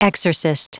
Prononciation du mot exorcist en anglais (fichier audio)
Prononciation du mot : exorcist